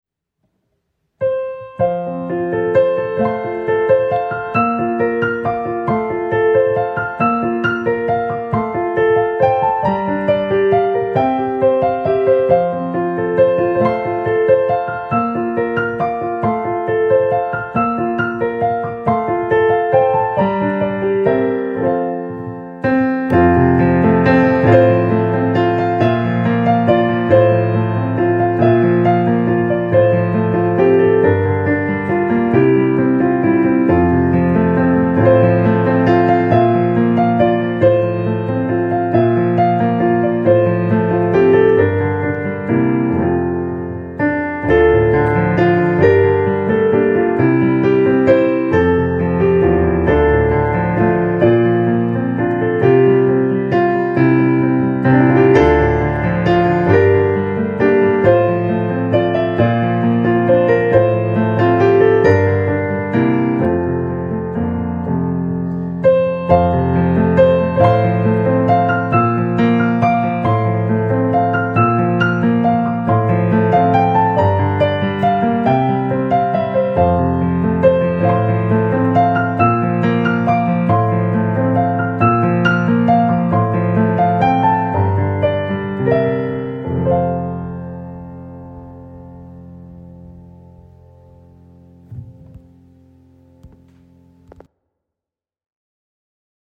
Piano Instrumental